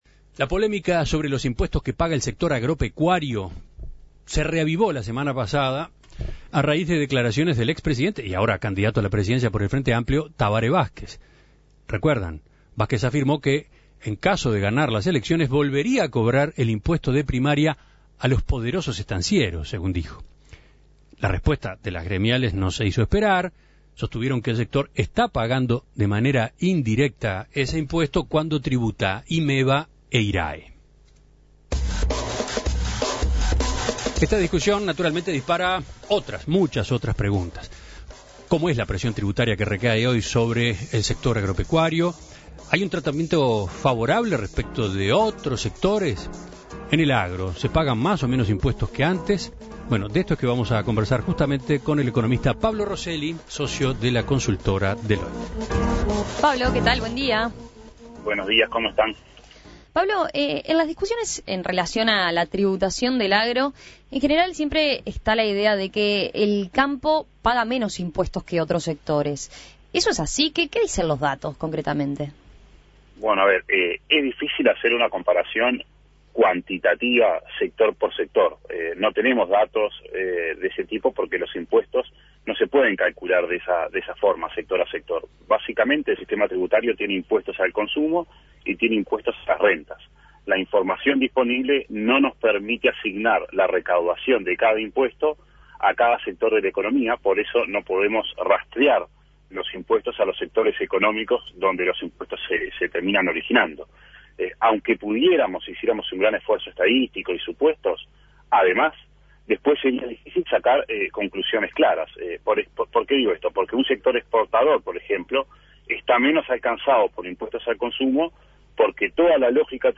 Análisis del economista